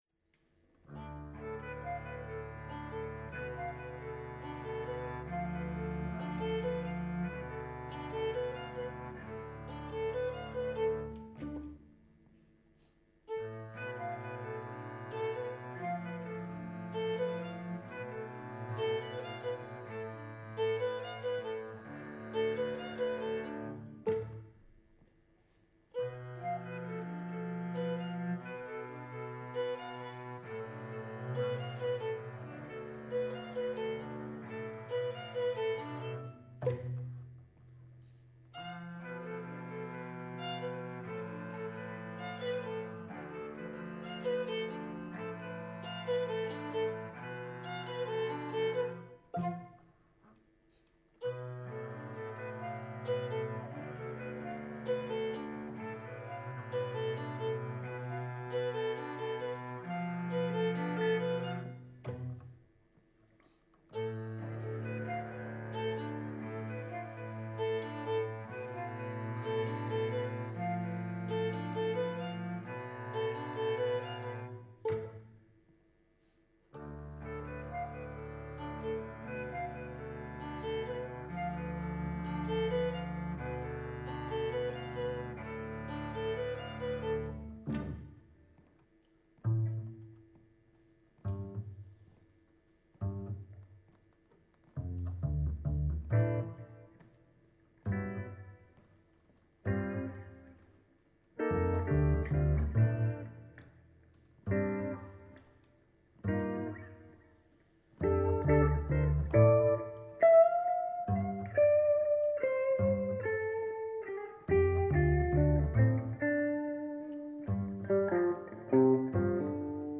flauto
violino
violoncello
contrabbasso